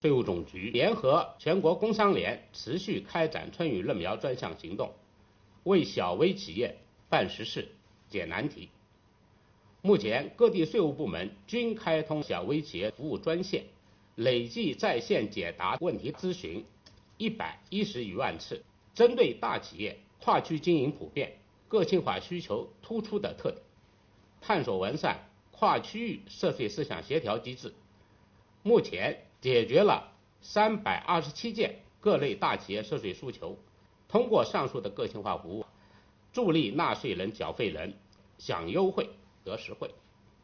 近日，国家税务总局召开新闻发布会，就税收大数据反映经济发展情况、税务部门学党史办实事扎实推进办税缴费便利化、打击涉税违法犯罪等内容进行发布并回答记者提问。会上，国家税务总局纳税服务司司长韩国荣介绍了“我为纳税人缴费人办实事暨便民办税春风行动”取得的进展和成效。